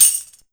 150JAMTAMB-R.wav